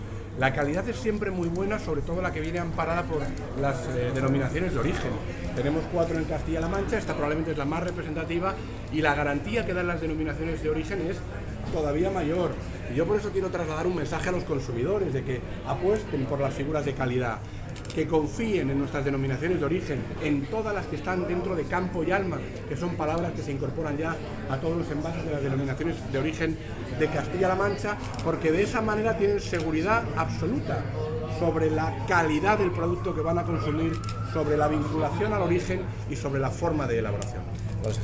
Corte voz Francisco Martínez Arroyo